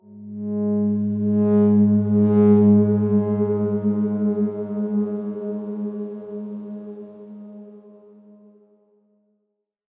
X_Darkswarm-G#2-mf.wav